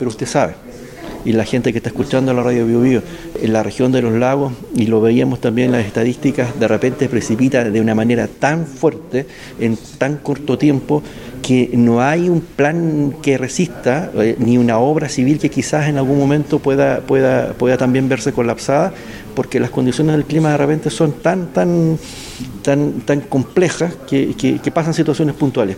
Hoy el actual seremi de Obras Públicas, Juan Alvarado, advirtió que la construcción de puentes y carreteras ha evolucionado, al igual que la labor que hacen concesionarios y empresas globales a fin de garantizar transitabilidad.